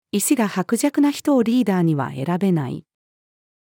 意志が薄弱な人をリーダーには選べない。-female.mp3